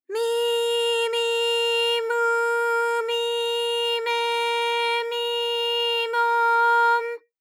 ALYS-DB-001-JPN - First Japanese UTAU vocal library of ALYS.
mi_mi_mu_mi_me_mi_mo_m.wav